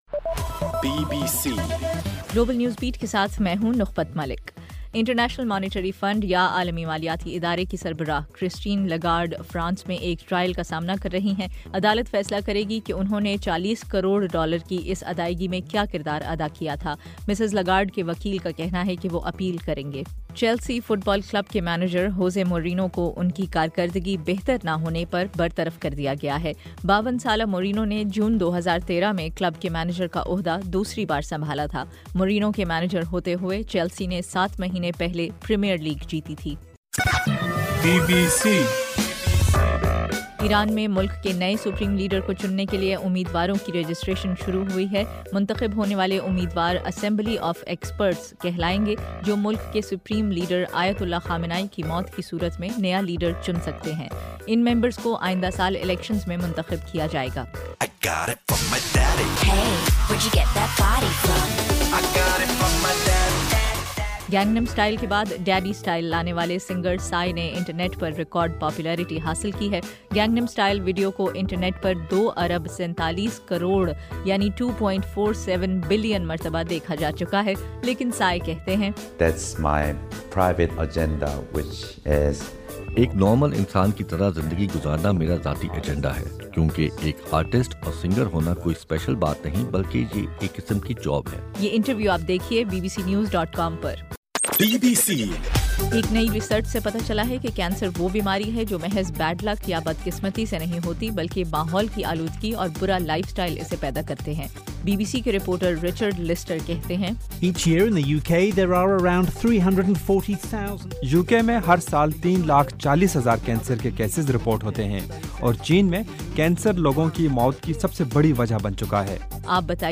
دسمبر 18 صبح 1 بجے کا گلوبل نیوز بیٹ بلیٹن